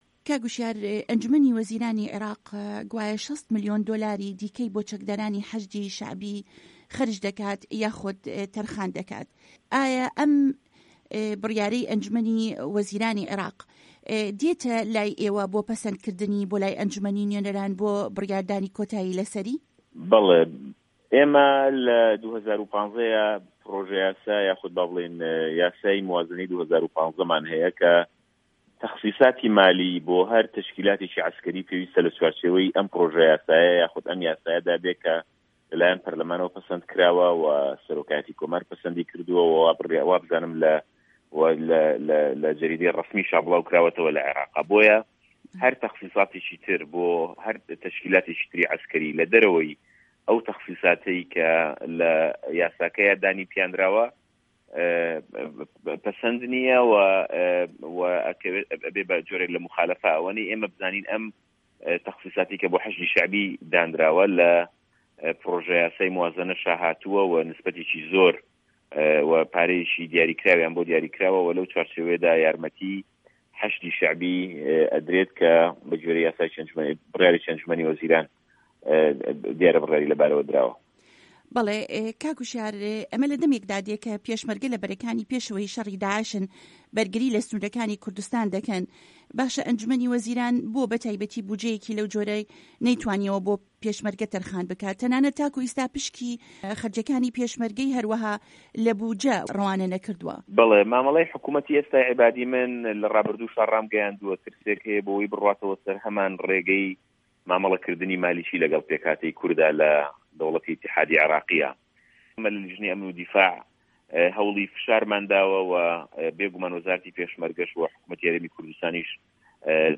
وتووێژی هوشیار عه‌بدوڵڵا